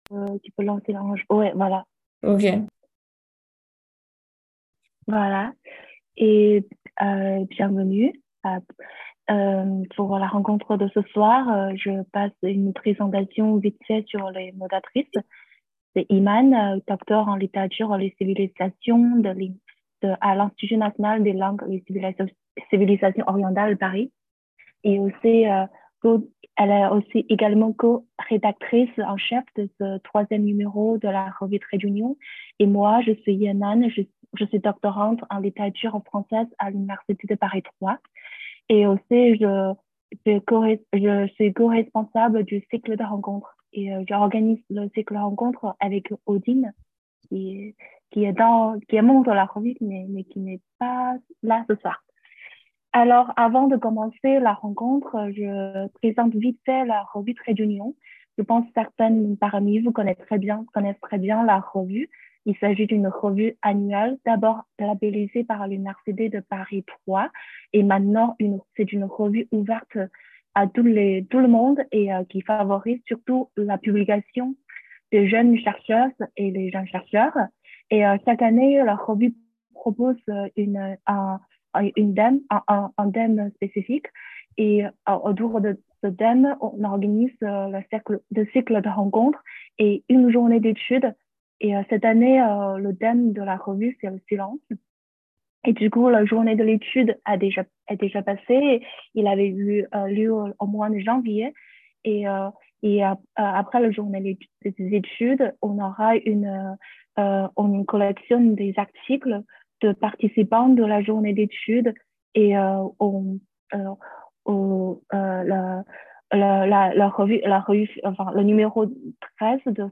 Rencontre Traits-d’Union 6 février 2023